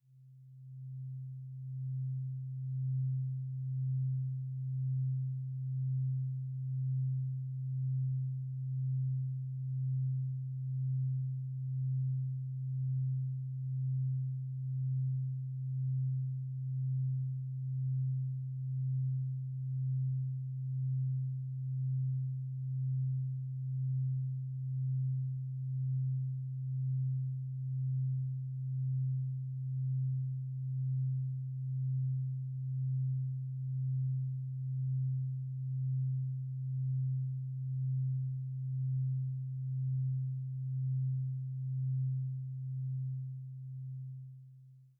Duration: 0:45 · Genre: Downtempo · 128kbps MP3